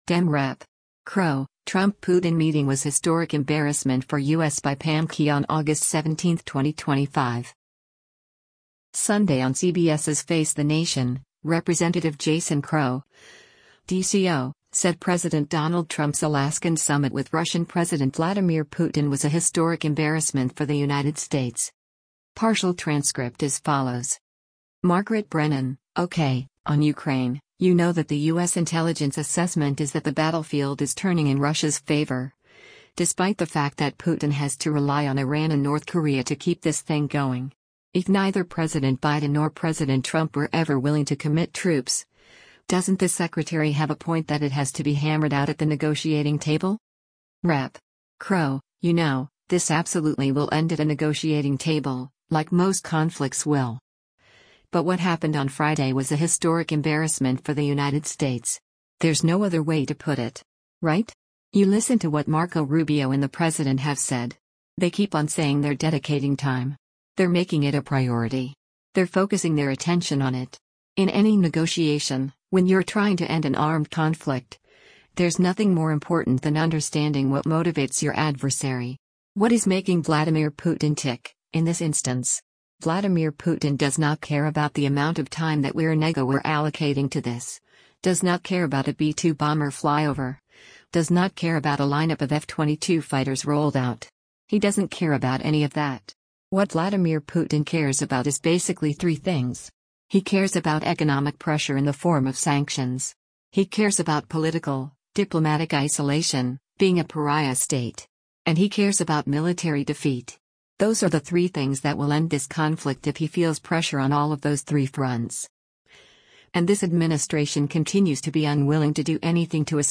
Sunday on CBS’s “Face the Nation,” Rep. Jason Crow (D-CO) said President Donald Trump’s Alaskan summit with Russian President Vladimir Putin was a “historic embarrassment for the United States.”